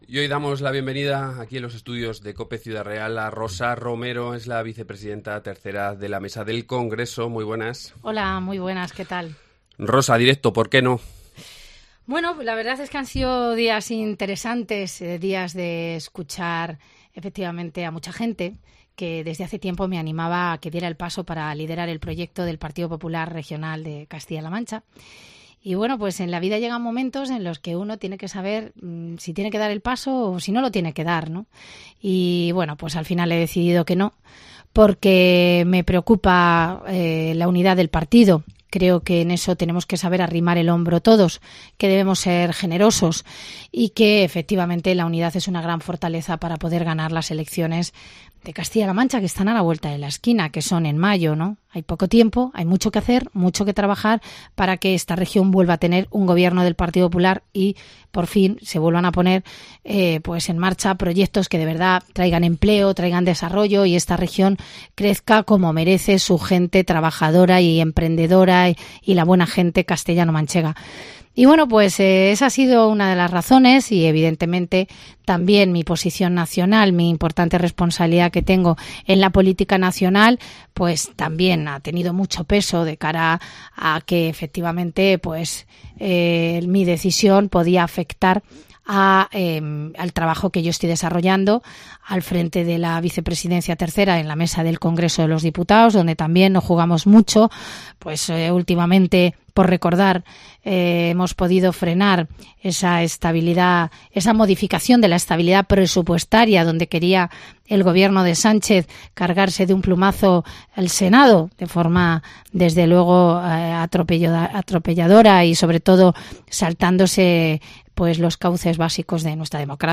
Hoy hablamos con ella en Cope Ciudad Real.